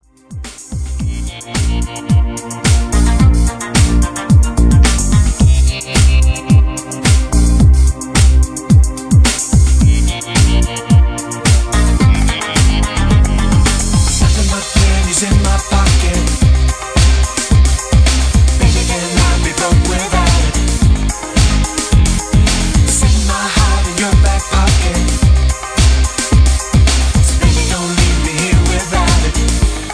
Tags: backing tracks , karaoke